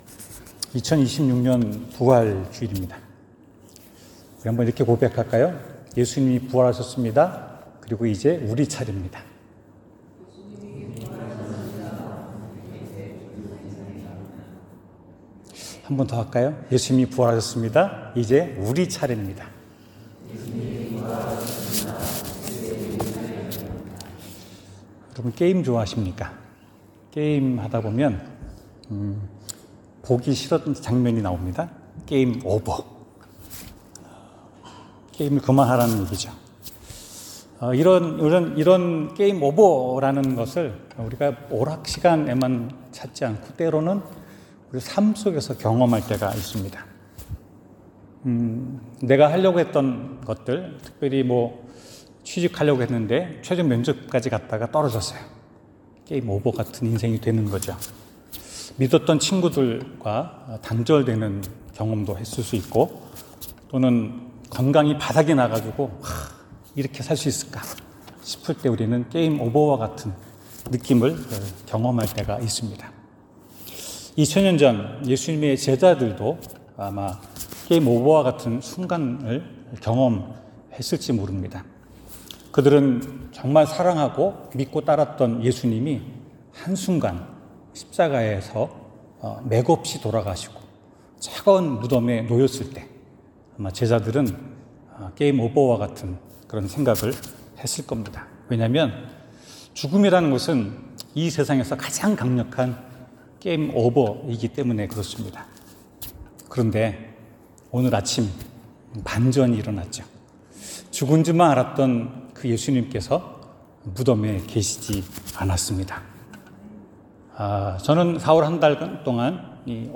성경: 누가복음 24:1-12 설교